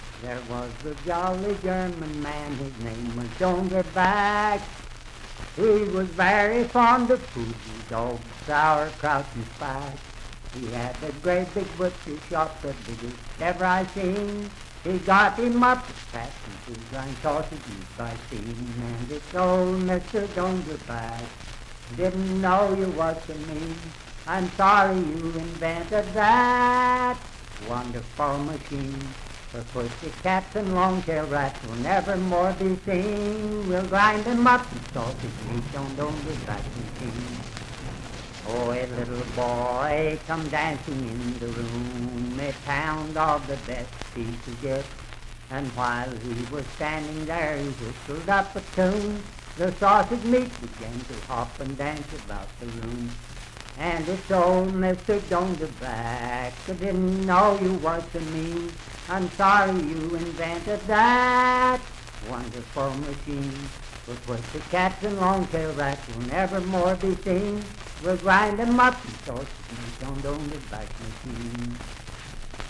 Unaccompanied vocal music
Voice (sung)
Wood County (W. Va.), Parkersburg (W. Va.)